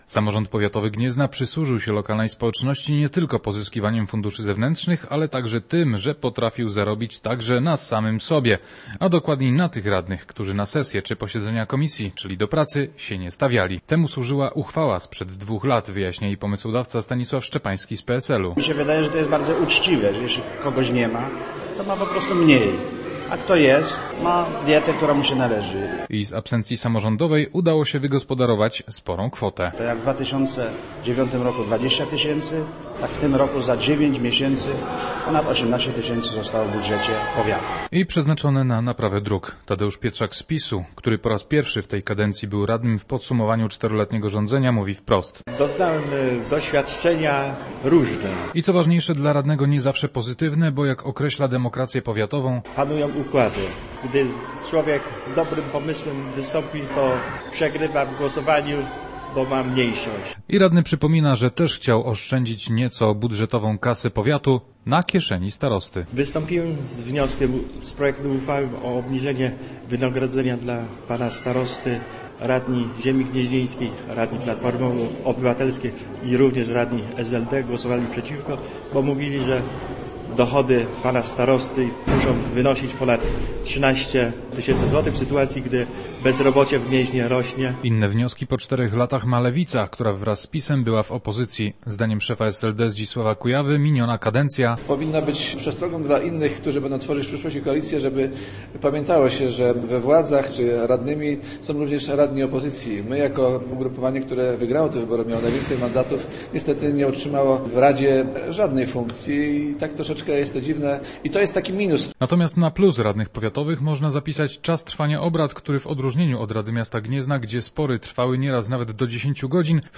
Nasz reporter był na ostatniej sesji samorządu powiatowego w Gnieźnie. Poddał on ocenie radnych "debiutantów" i "weteranów".